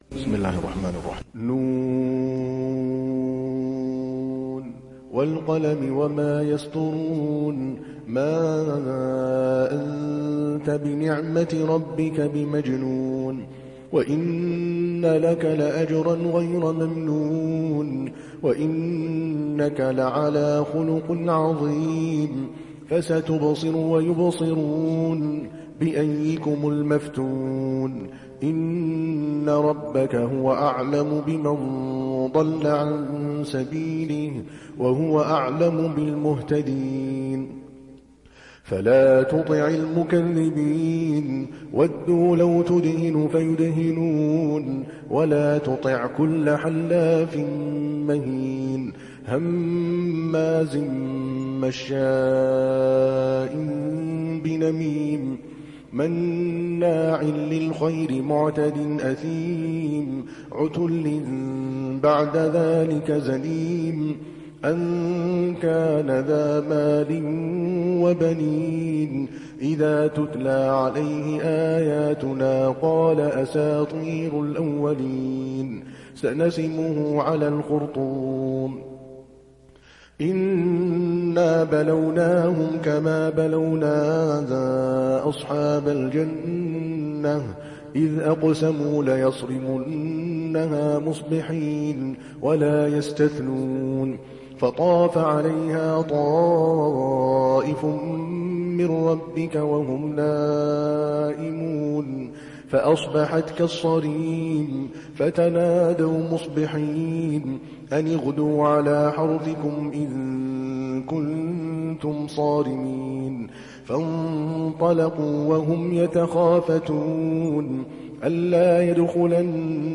تحميل سورة القلم mp3 بصوت عادل الكلباني برواية حفص عن عاصم, تحميل استماع القرآن الكريم على الجوال mp3 كاملا بروابط مباشرة وسريعة